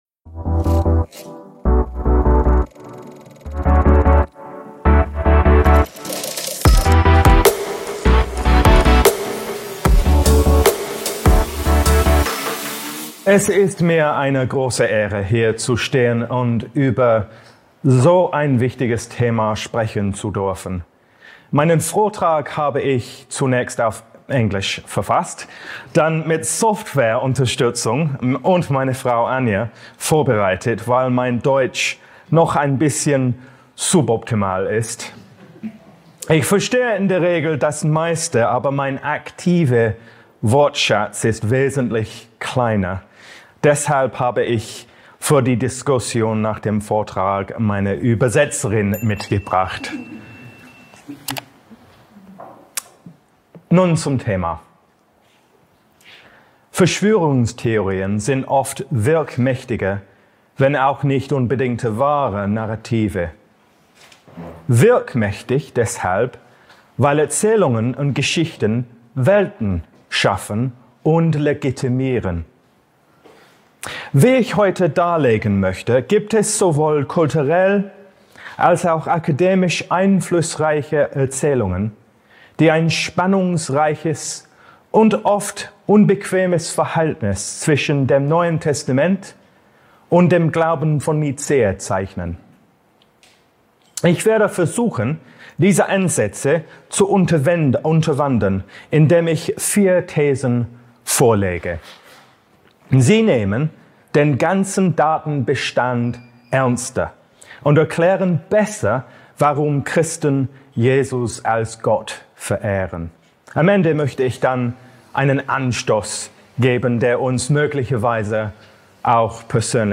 in diesem Vortrag auf der diesjährigen Jahrestagung des Instituts für Glaube und Wissenschaft